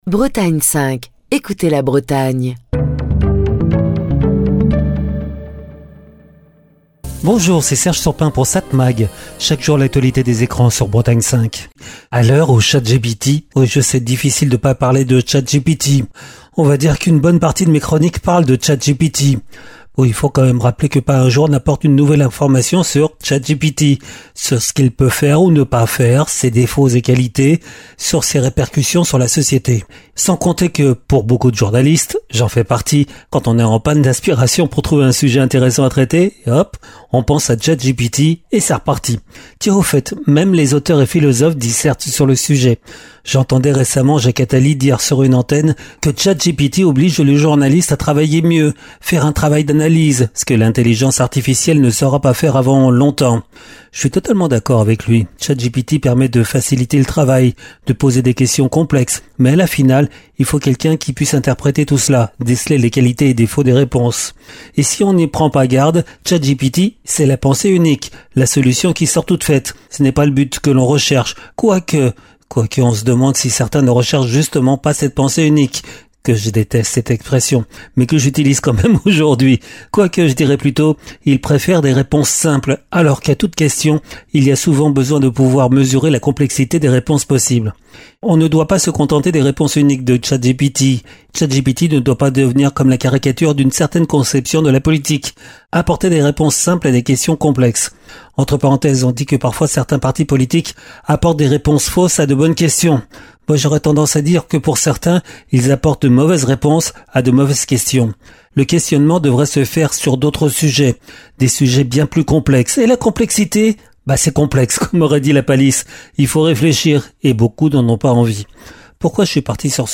Chronique du 13 novembre 2025.